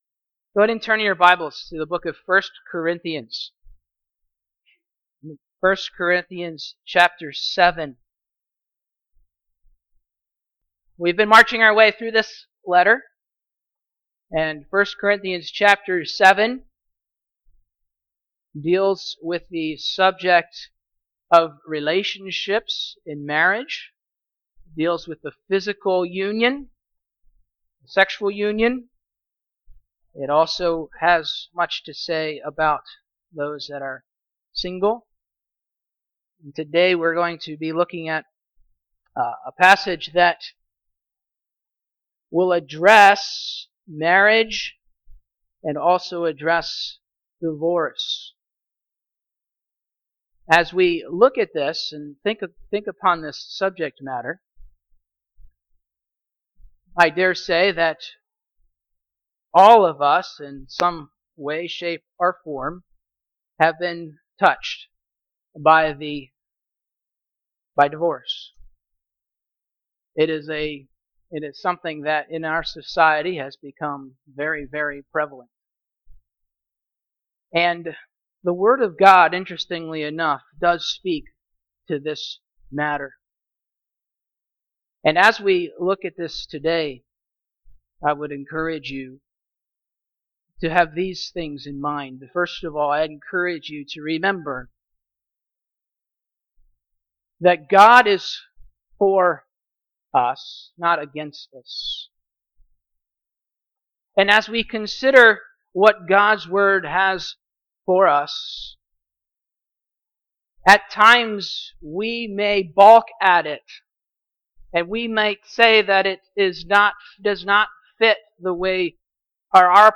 1 Corinthians Passage: 1 Corinthians 7:8-11 Service: Sunday Morning « God